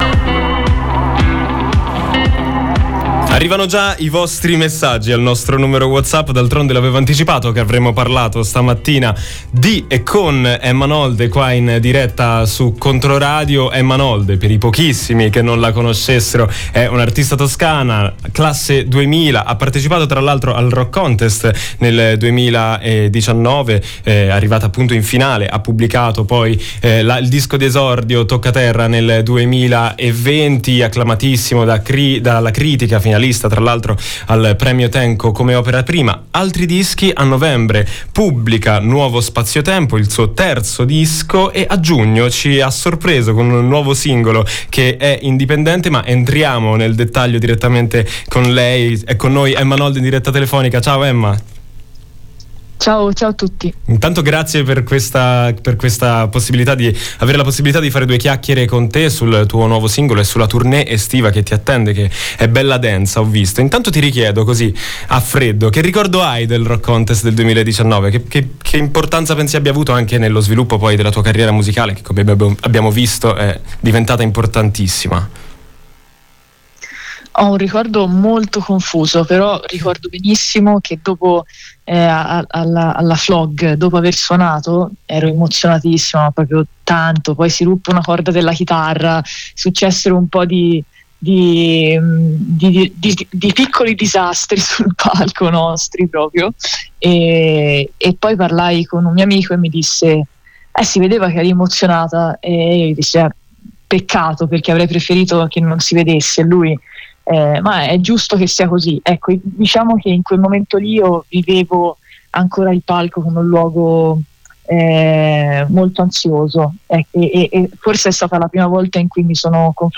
l’intervista “Indipendente”.